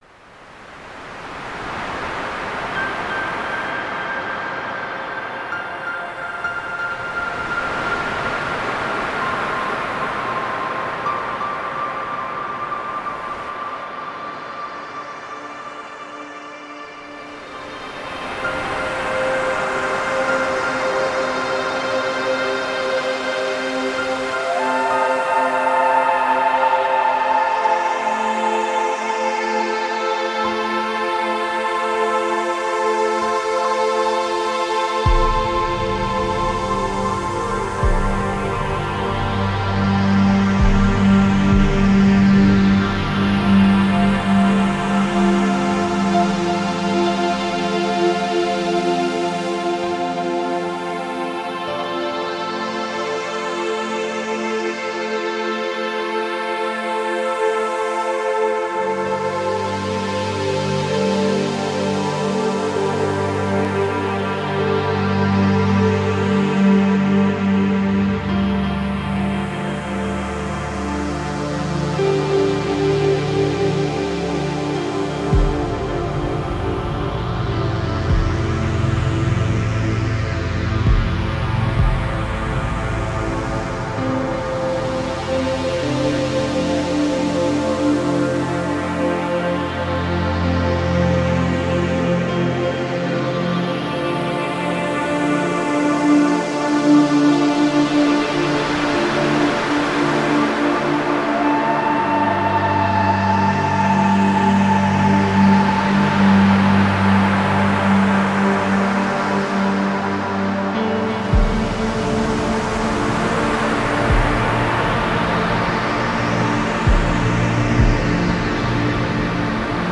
более красивую музыку в стиле relax
пусть не обижается но с такой музыкой только в последний путь провожать
вам же сказали что это в стиле RELAX